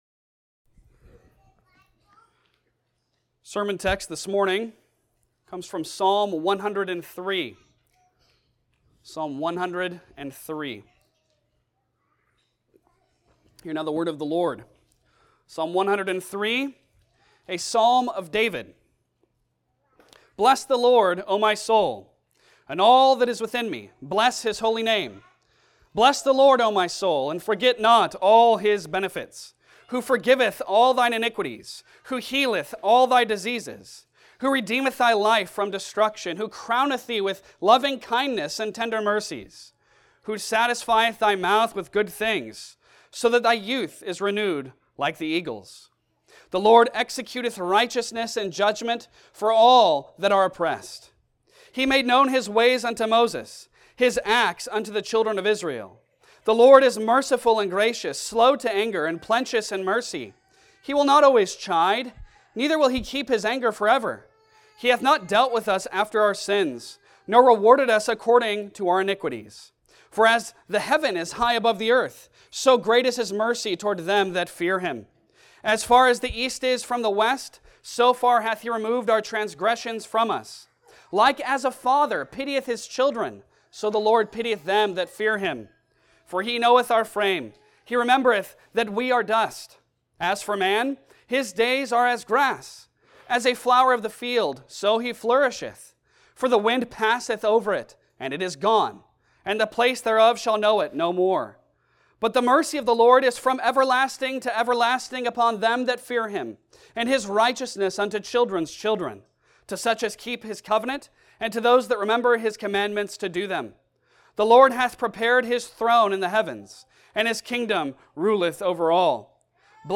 Psalms Passage: Psalm 103 Service Type: Sunday Sermon Download Files Bulletin « Leaning on Christ Baptism